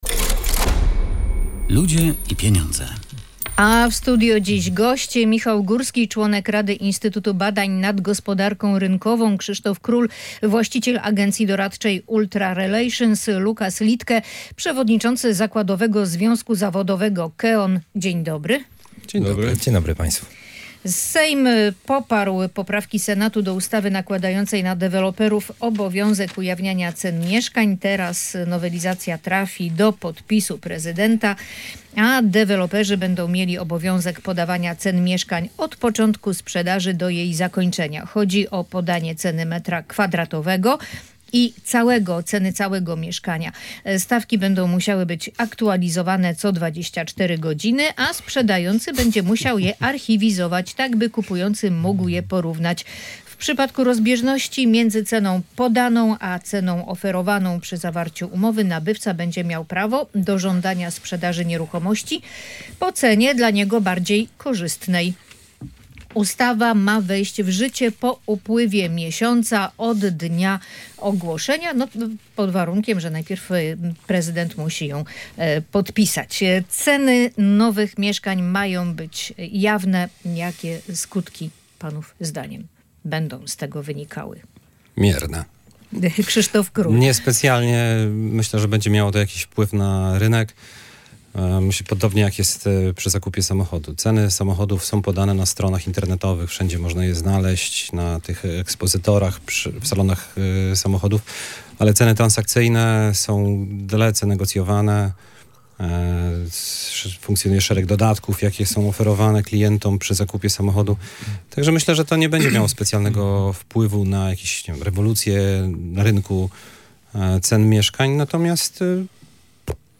Ustawa nakładająca na deweloperów obowiązek ujawniania cen mieszkań czeka już tylko na podpis prezydenta. Chodzi o publikowanie ceny metra kwadratowego i całego mieszkania. Na ten temat dyskutowali goście audycji „Ludzie i Pieniądze”